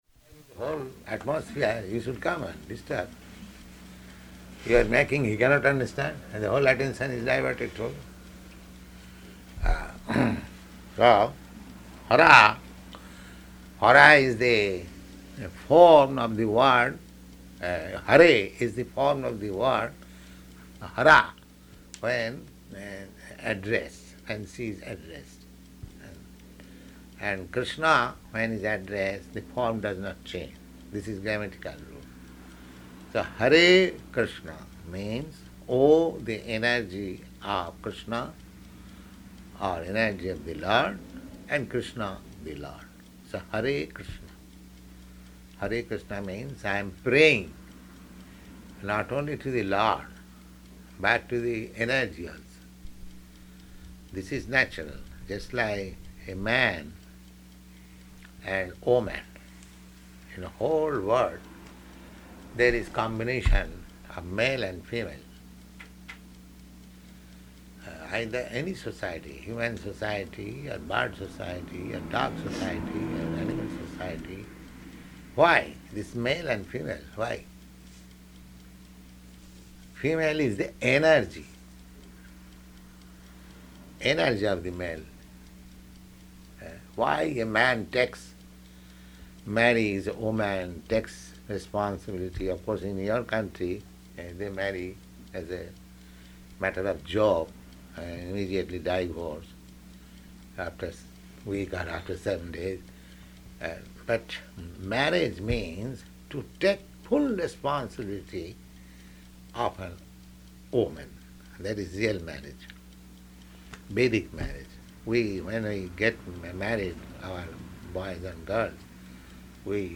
Room Conversation
Room Conversation --:-- --:-- Type: Conversation Dated: October 20th 1968 Location: Seattle Audio file: 681020R1-SEATTLE.mp3 Prabhupāda: ...the whole atmosphere, you should come and disturb.